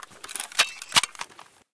draw.ogg